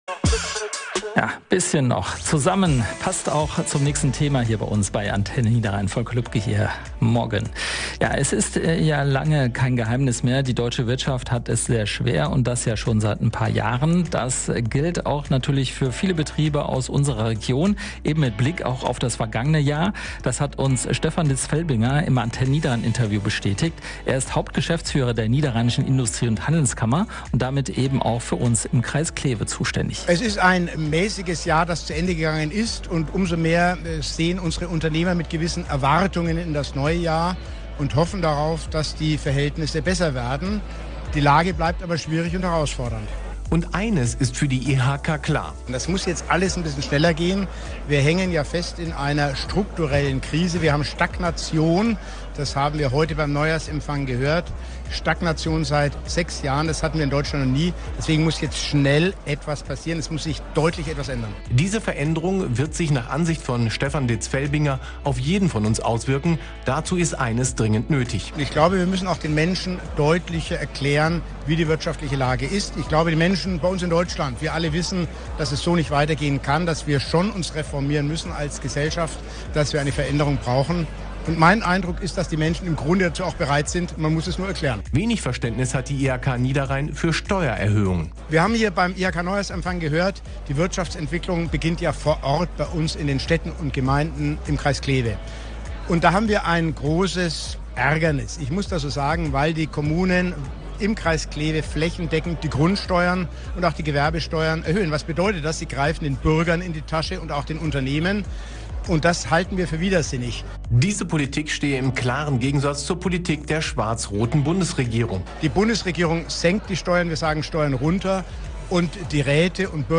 Neujahrsempfang der niederrheinischen IHK